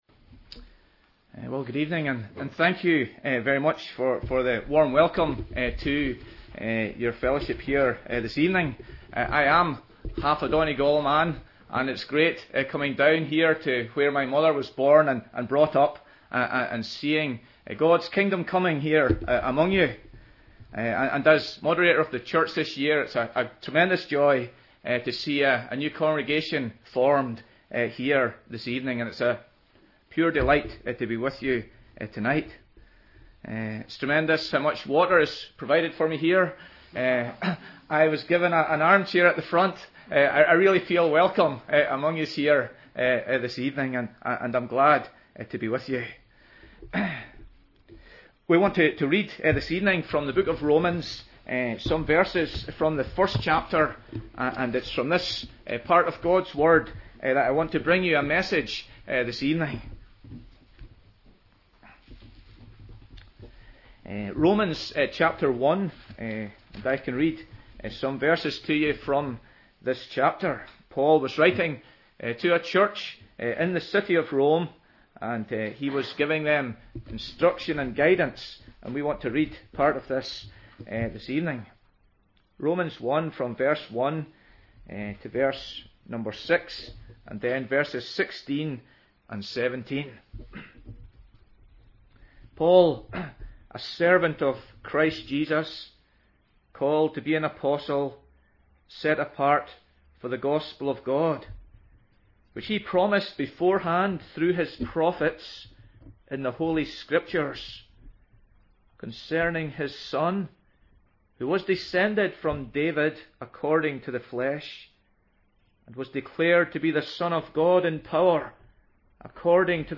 Hear the latest sermons preached in NLF, or browse the back catalogue to find something to feed your soul.